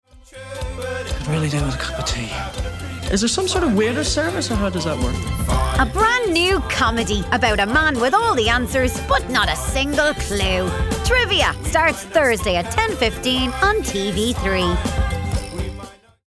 30/40's Irish,
Fun/Animated/Characterful
Trivia – Promo